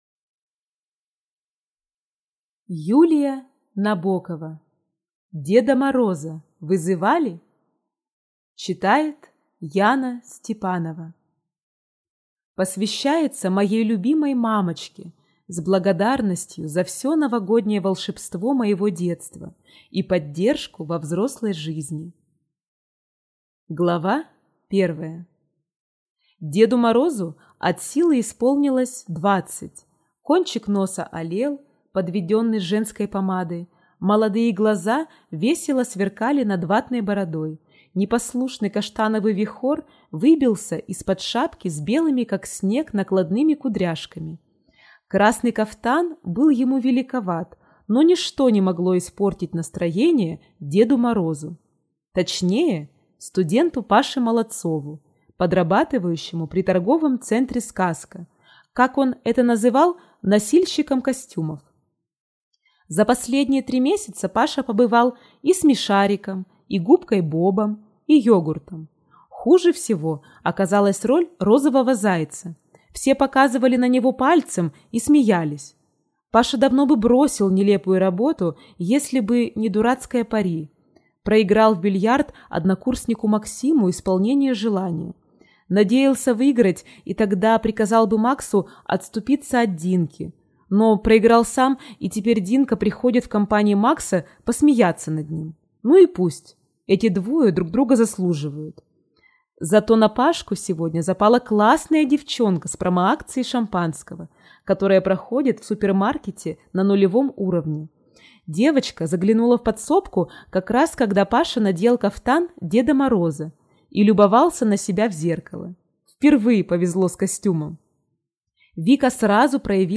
Аудиокнига Деда Мороза вызывали?